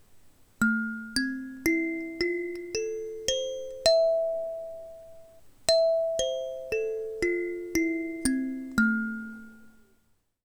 Seven chrome-plated steel keys tuned to specific notes deliver a light sound that is pleasant for a recreational or classroom setting. The solid wood body projects the notes being played to deliver full and rich tones.